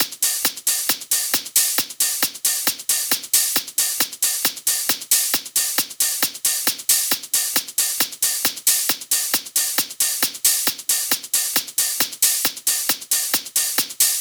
TI CK7 135 Hh Loop.wav